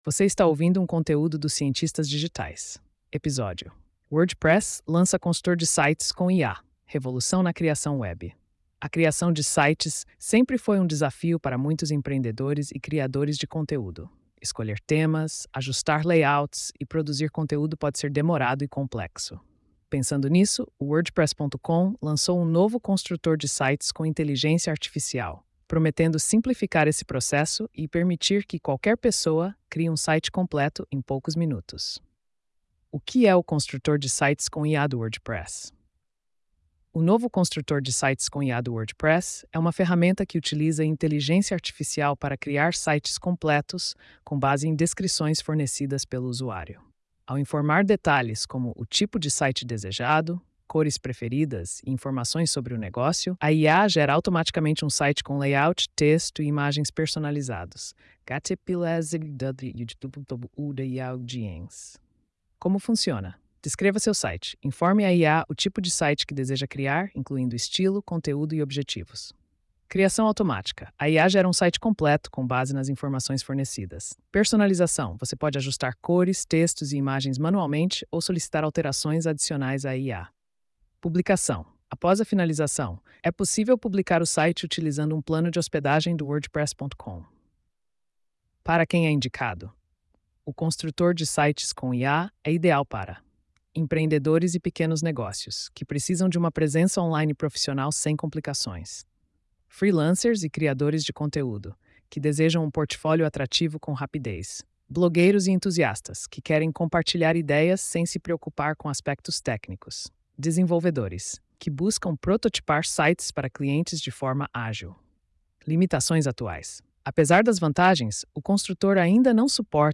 post-3098-tts.mp3